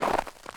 footstep_snow5.ogg